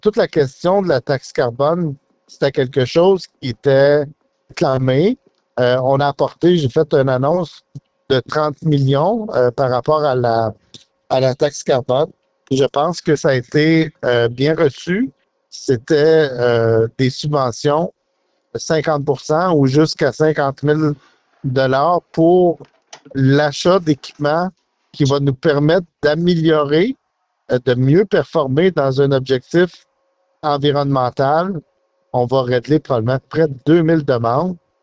» Lors de son entrevue avec VIA 90.5 FM, M. Martel a également mentionné qu’il avait pris le pouls de plusieurs entreprises de la province en sillonnant la province au cours de ses six derniers mois.